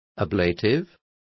Complete with pronunciation of the translation of ablatives.